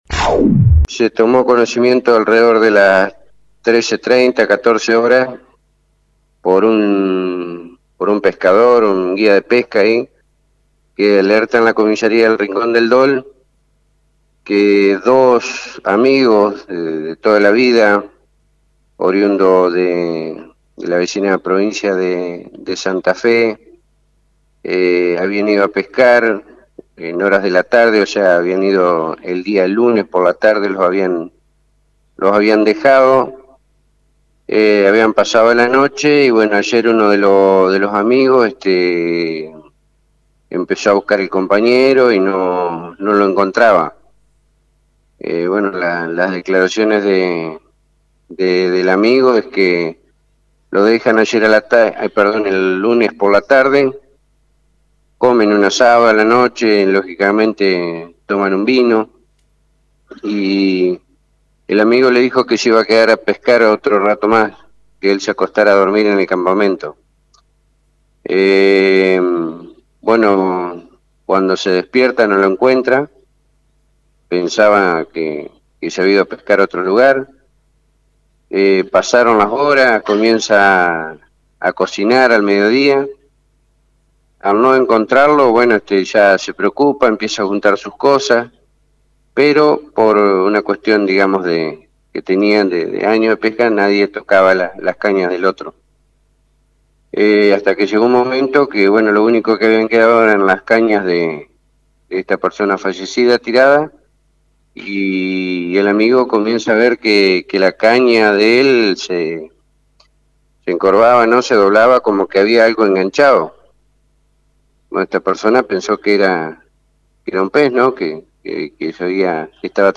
El trágico hecho fue relatado en FM 90.3 por el Jefe de Policía Omar Regondi, quien comentó que la persona que encontró el cuerpo primero pidió ayuda a otro pescador y luego se comunicó con la policía, quienes se hicieron presentes en el lugar del hecho junto con el fiscal de turno.